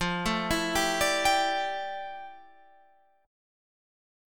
FM13 chord